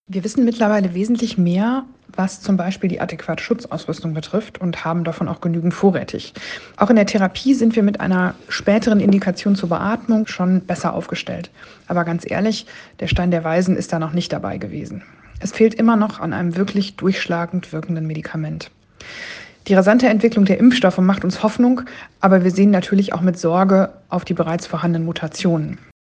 Virologin